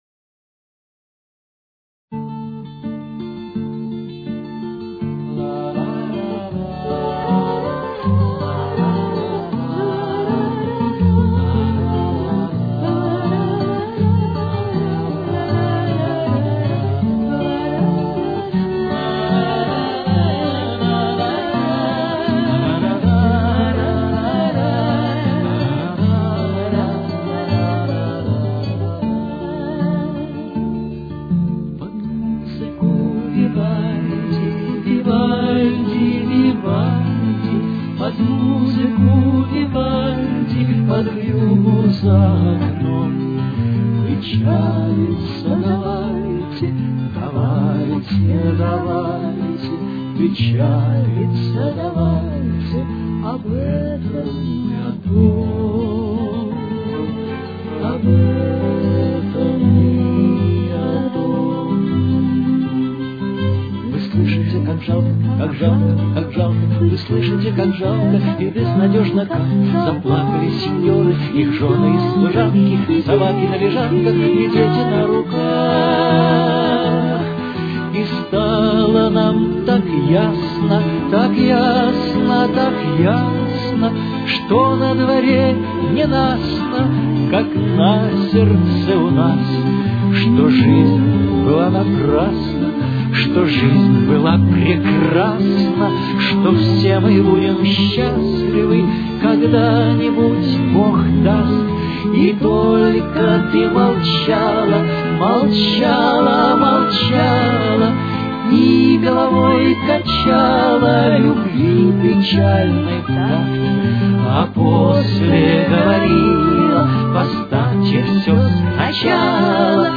Темп: 82.